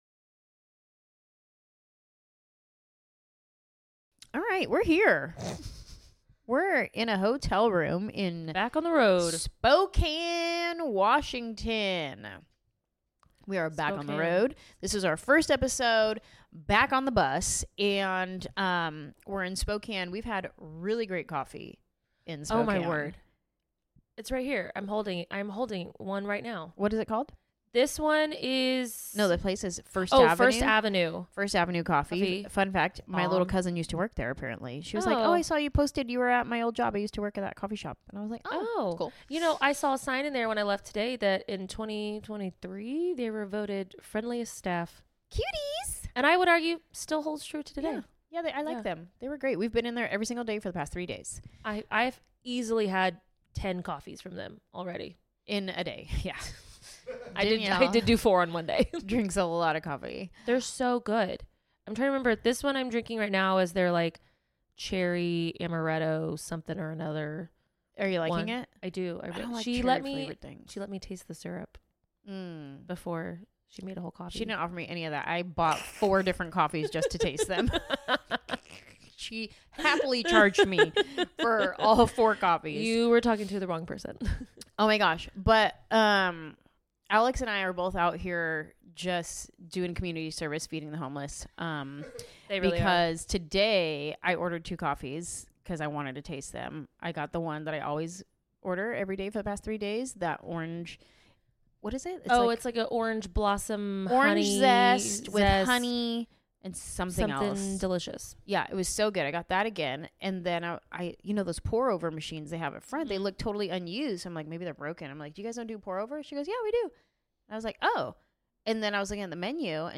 sit down in their hotel room